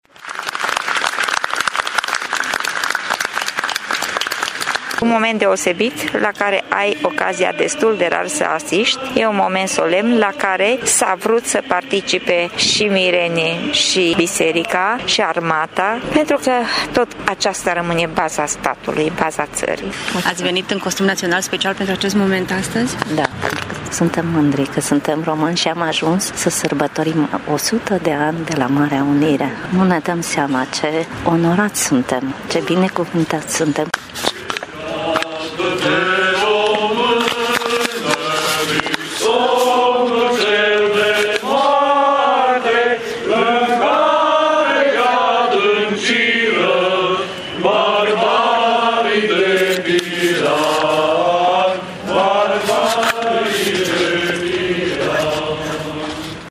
Arborarea drapelului s-a făcut în salutul militarilor și în aplauzele târgumureșenilor prezenți care, cu această ocazie, se pregătesc sufletește de marea sărbătoare de la 1 Decembrie: